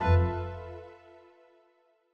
Longhorn 8 - User Account Control.wav